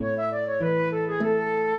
minuet3-6.wav